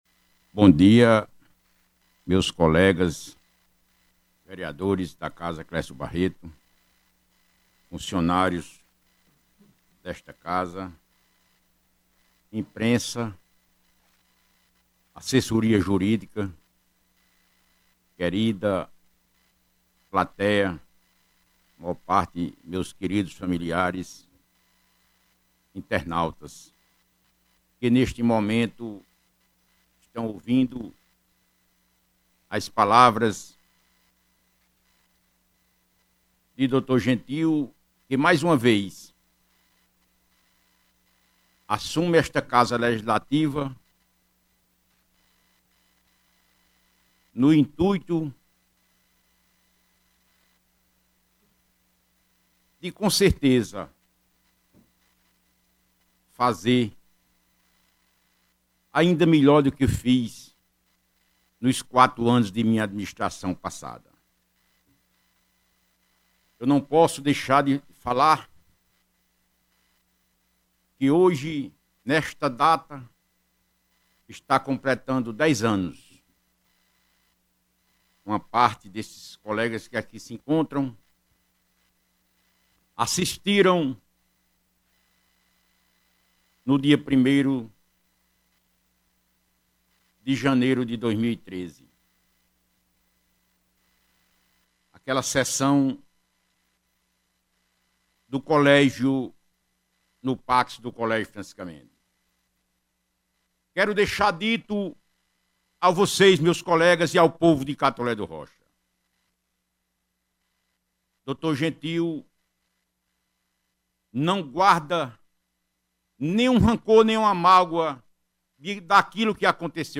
Após ser empossado, o vereador Dr Gentil Barreto proferiu o seu primeiro pronunciamento como Presidente da casa Clécio Barreto.
Dr-Gentil-Barreto-Pronunciamento.mp3